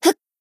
BA_V_Kayoko_Newyear_Battle_Shout_1.ogg